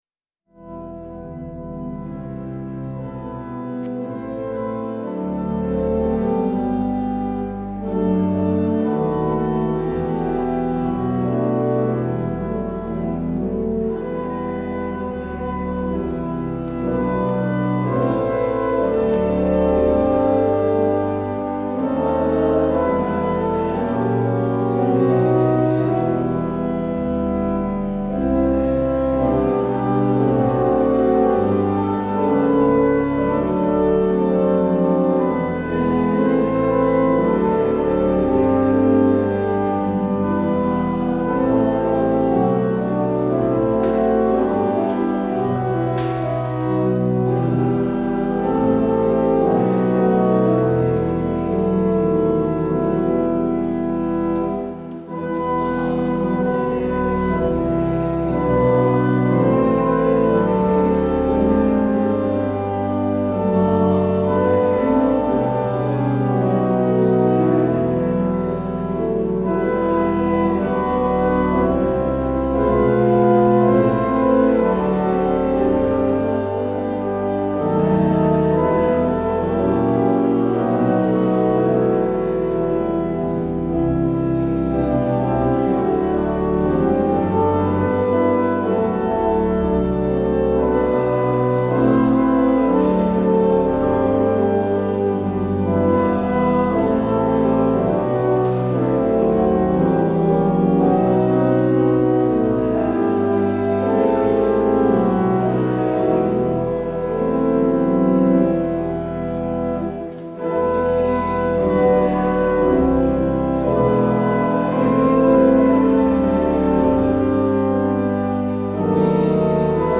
 Luister deze kerkdienst hier terug: Alle-Dag-Kerk 26 juli 2022 Alle-Dag-Kerk https